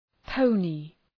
Προφορά
{‘pəʋnı}